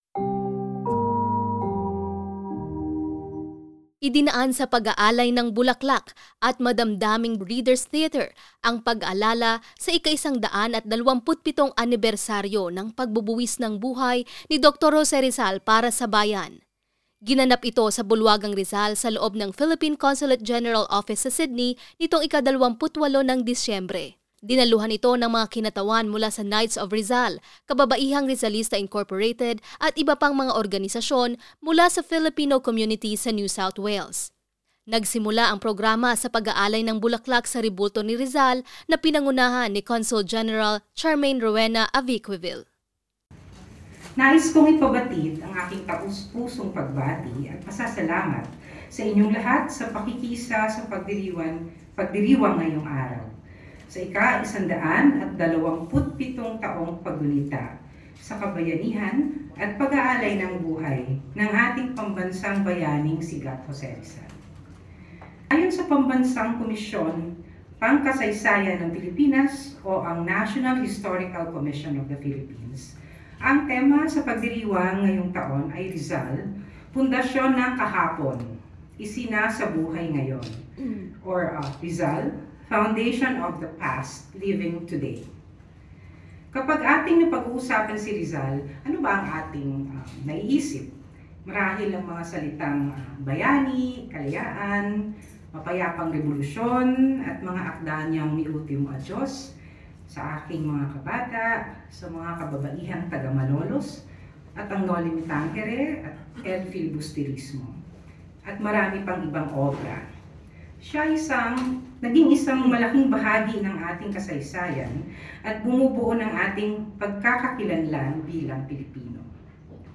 A tribute to Dr. Jose Rizal's enduring legacy through Reader's Theatre, focusing on his renowned novel 'Noli Me Tangere.'
rizal-day-readers-theatre.mp3